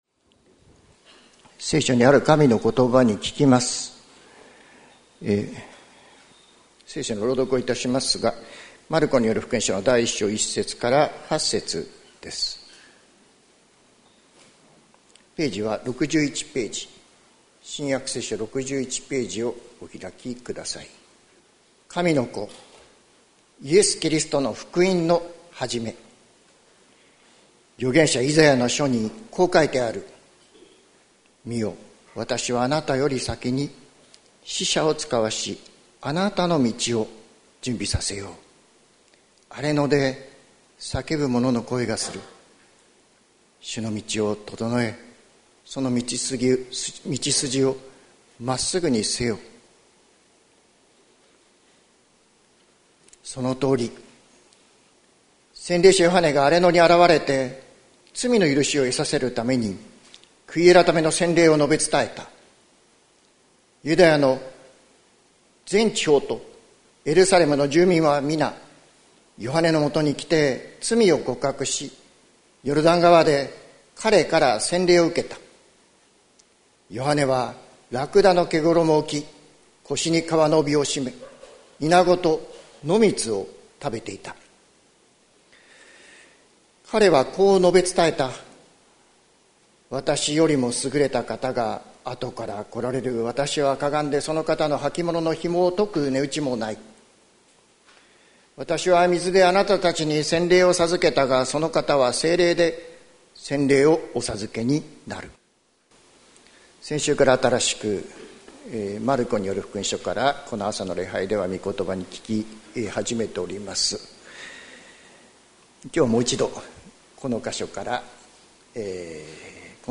説教アーカイブ。